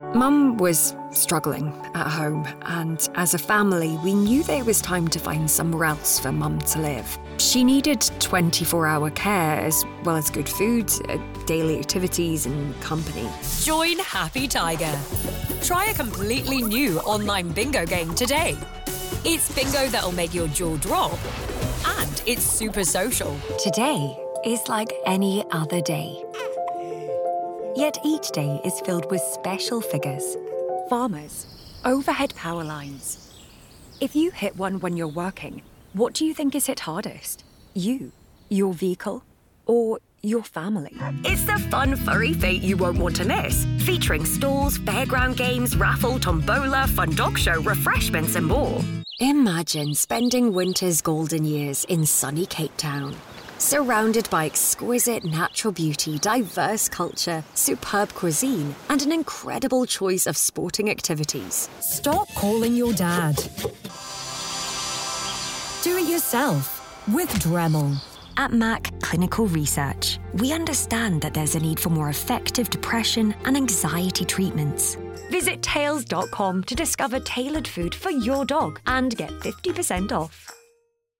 Inglés (escocés)
Estudio totalmente insonorizado con tratamiento acústico.
Contralto